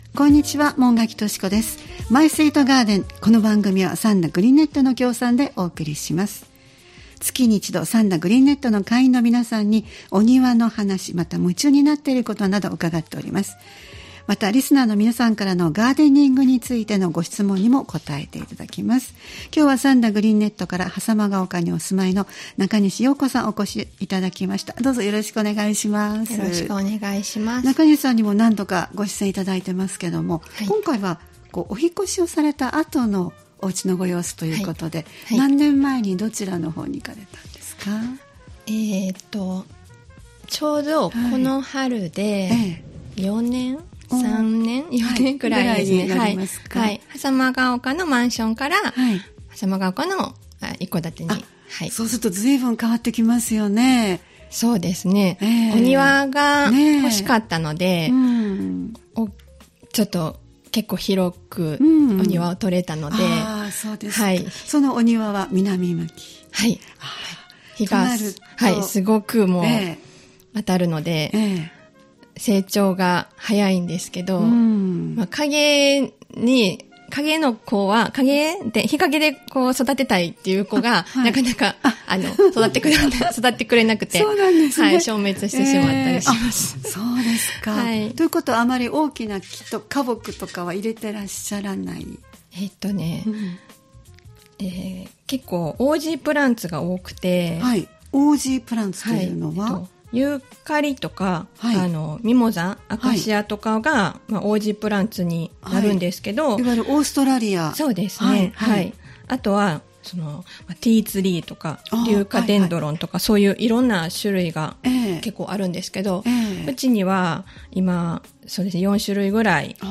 毎月第2火曜日は兵庫県三田市、神戸市北区、西宮市北部でオープンガーデンを開催されている三田グリーンネットの会員の方をスタジオにお迎えしてお庭の様子をお聞きする「マイスイートガーデン」（協賛：三田グリーンネット）をポッドキャスト配信しています（再生ボタン▶を押すと番組が始まります）